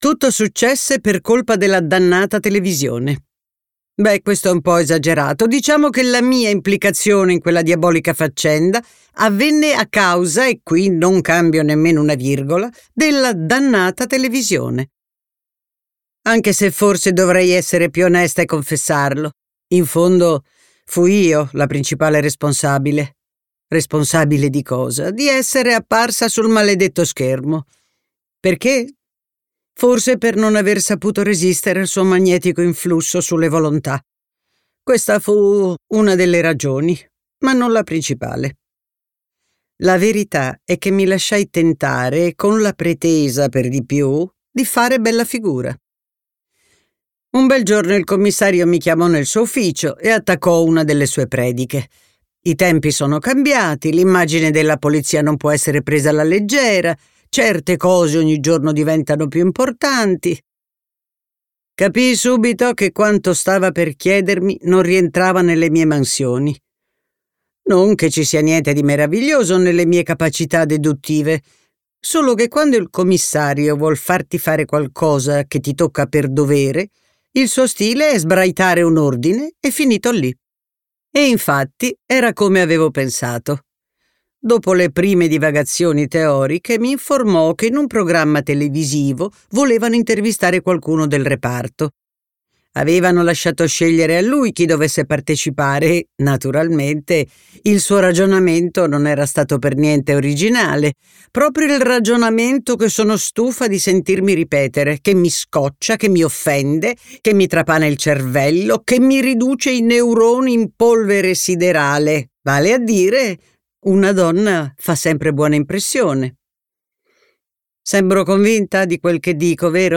letto da Anna Bonaiuto
Versione audiolibro integrale